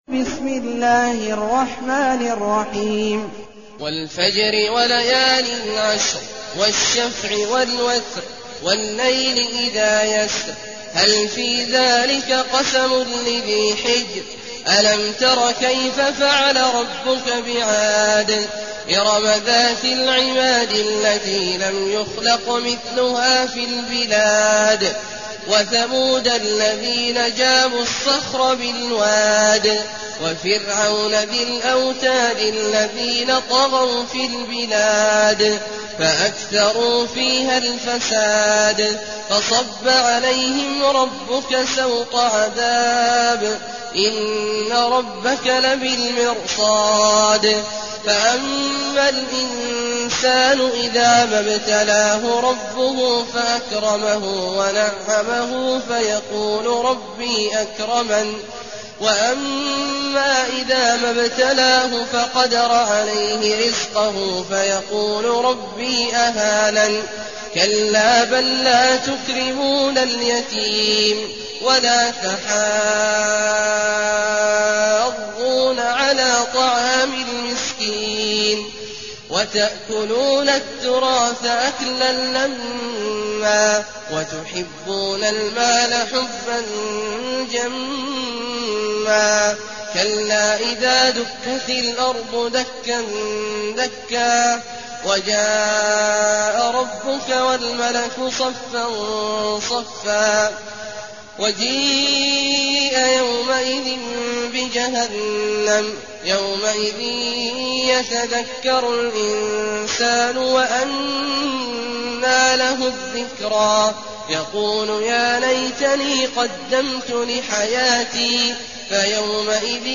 المكان: المسجد النبوي الشيخ: فضيلة الشيخ عبدالله الجهني فضيلة الشيخ عبدالله الجهني الفجر